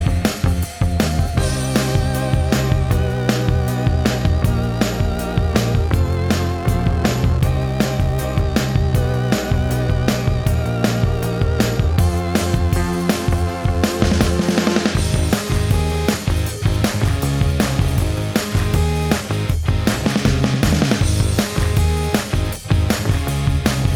No Lead Guitar Pop (2000s) 3:50 Buy £1.50